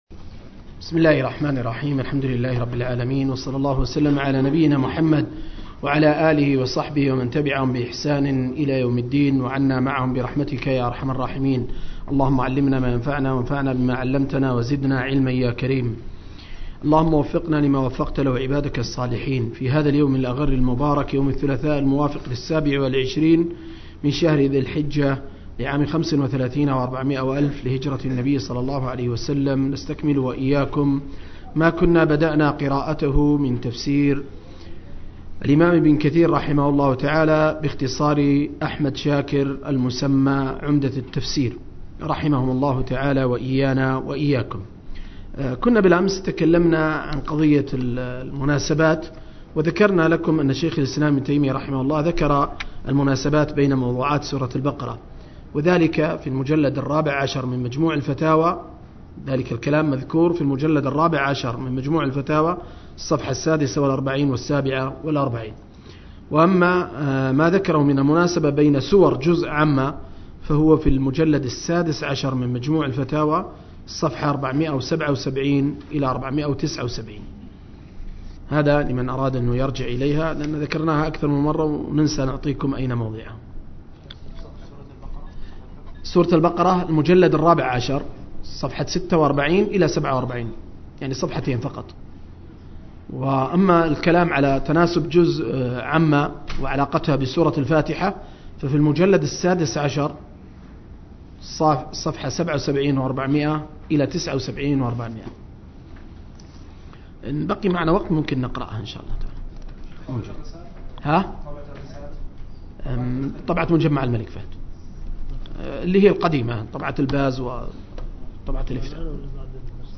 046- عمدة التفسير عن الحافظ ابن كثير – قراءة وتعليق – تفسير سورة البقرة (الآيتين 230-229)